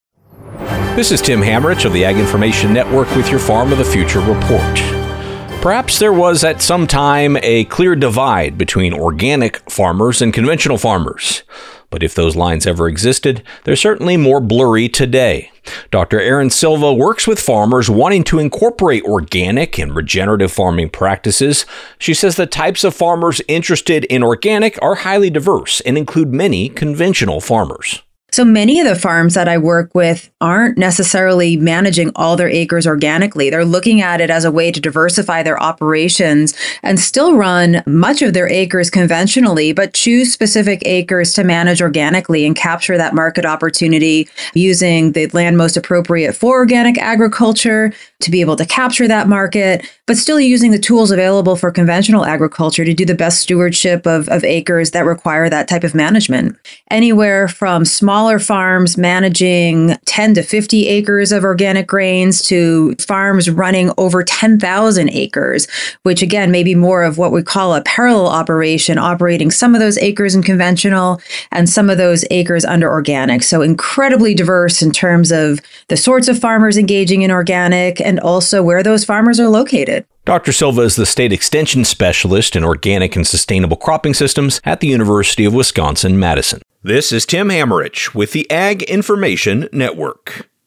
News Reporter